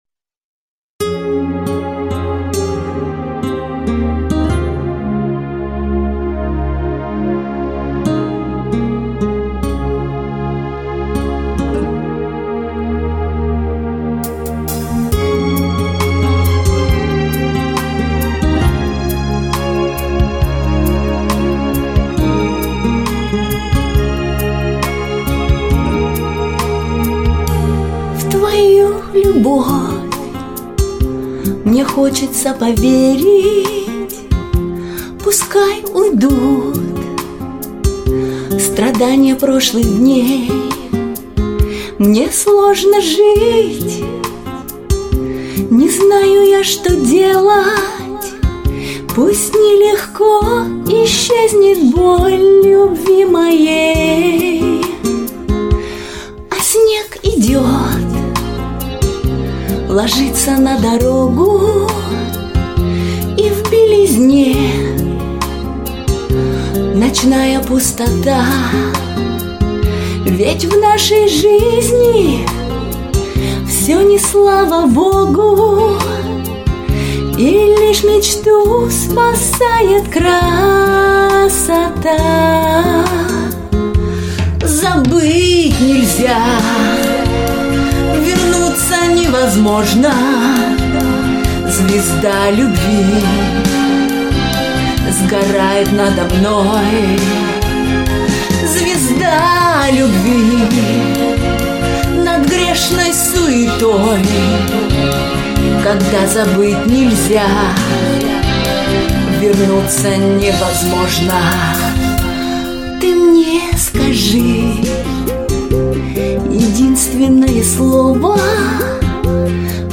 Получился прекрасный дуэт двух любящих особ.